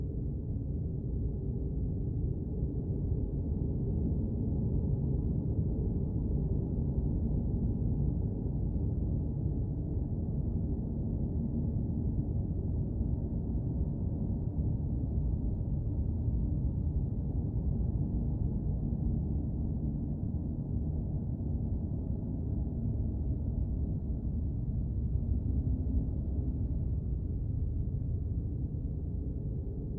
Ambiance_Cave_Dark_Loop_Stereo.wav